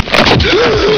The following sounds are from all the Alien movies made to date.
Bishop gasps as the Alien Queen rips him in two!
Bishop_gasp.wav